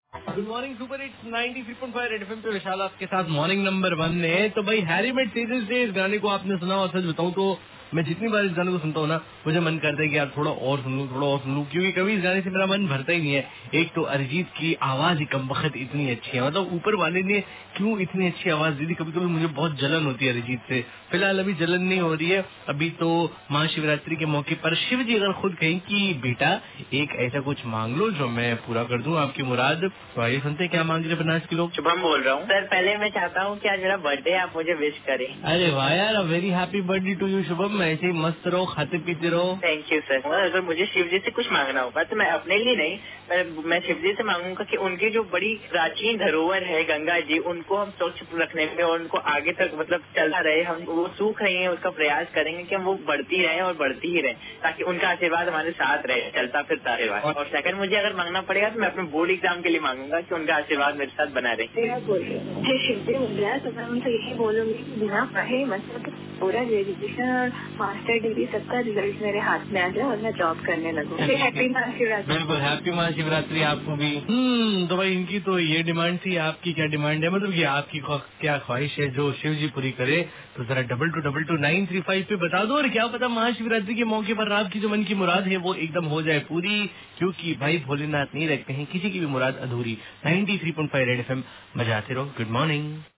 WITH CALLER